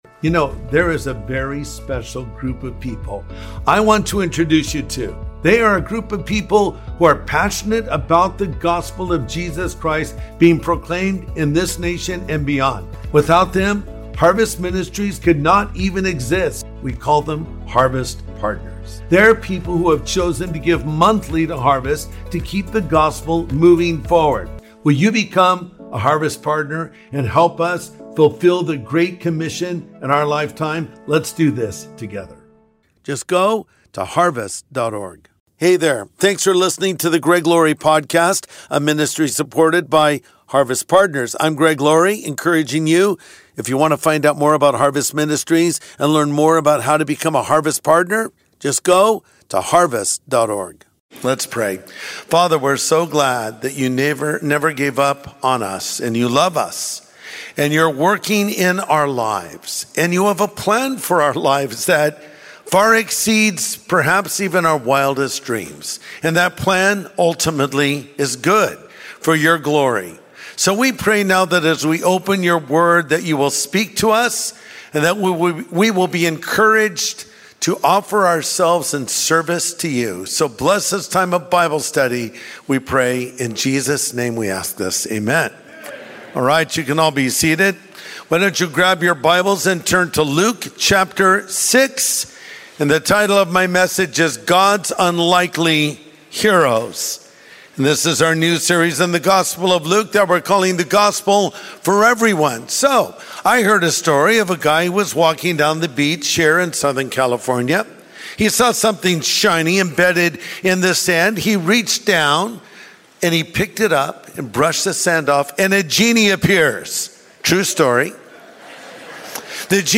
God’s Unlikely Heroes | Sunday Message Podcast with Greg Laurie